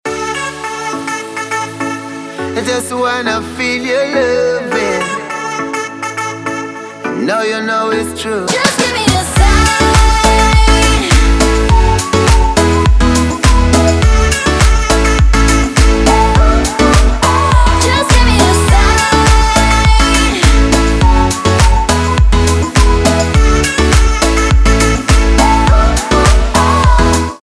поп
позитивные
dance